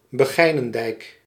Summary Description Nl-Begijnendijk.ogg Dutch pronunciation for "Begijnendijk" — male voice.